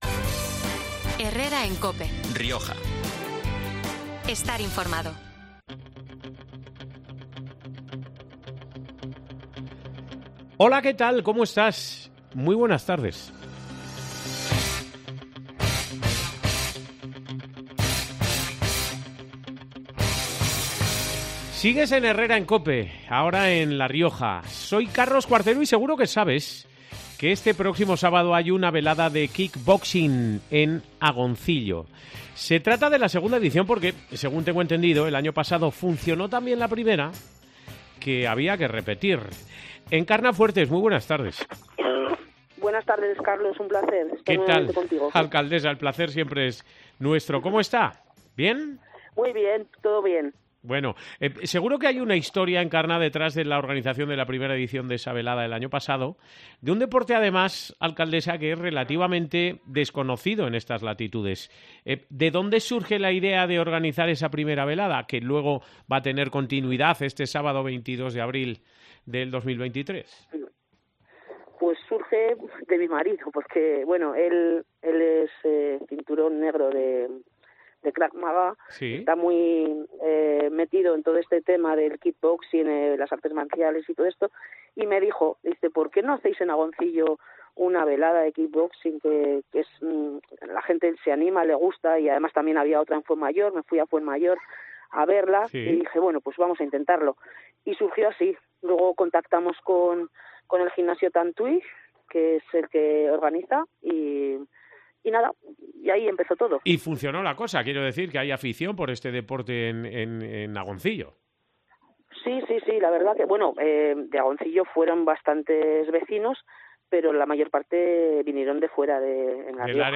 Encarna Fuertes, alcaldesa de la localidad, ha pasado este 18 de abril por los micrófonos de COPE Rioja para repasar diversos asuntos de actualidad.